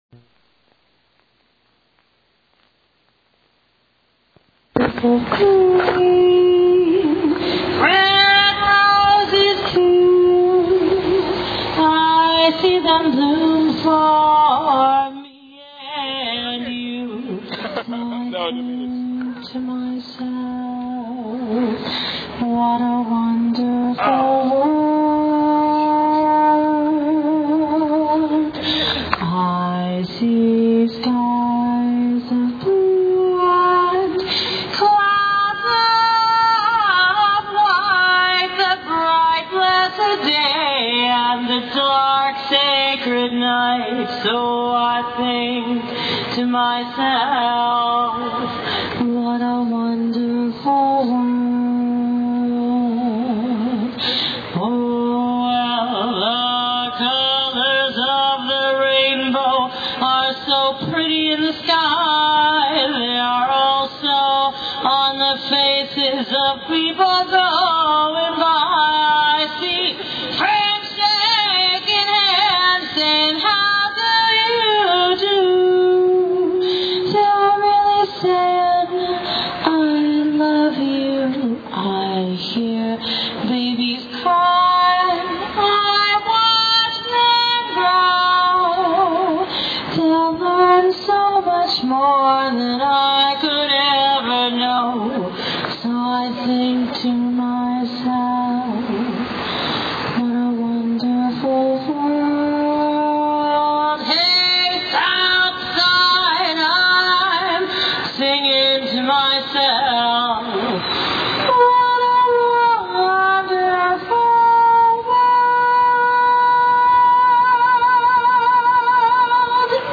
mp3 audio of southside re-convergence peace rally and march to market square
includes chants on the march to maket square.